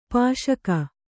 ♪ pāśaka